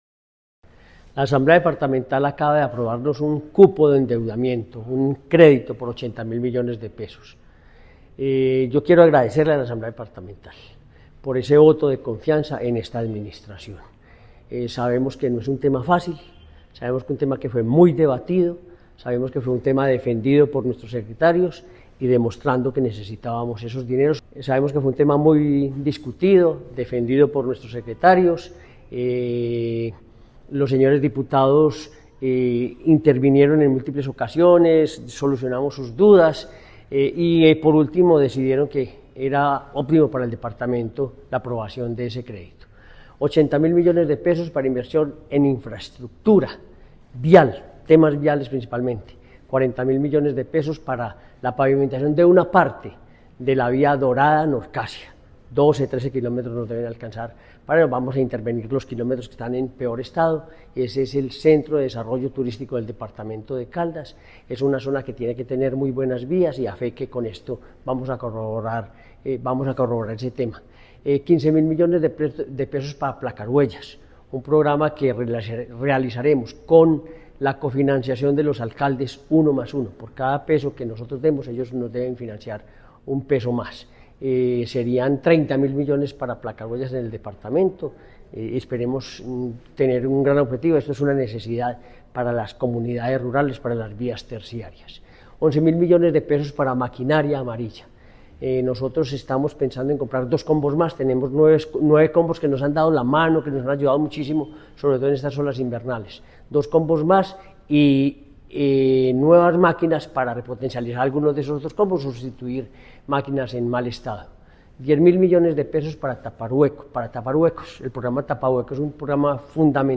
Gobernador de Caldas, Henry Gutiérrez Ángel.